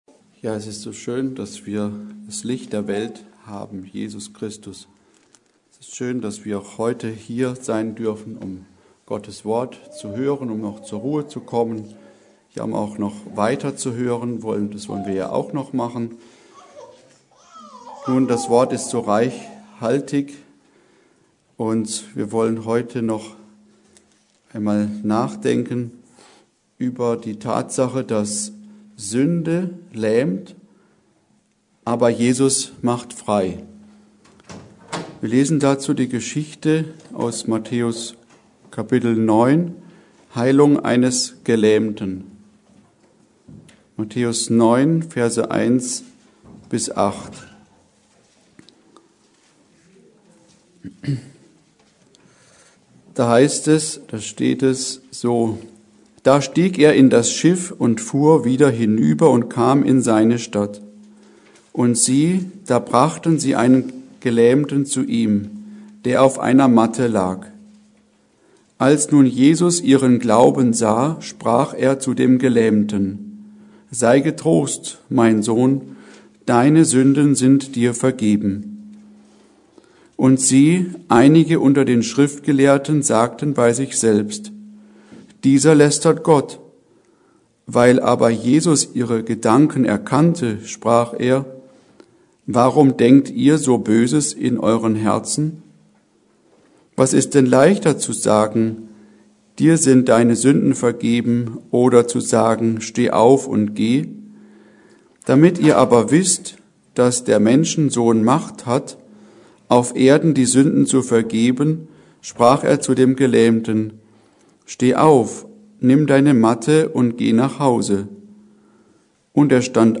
Predigt: Sünde lähmt, aber Jesus befreit!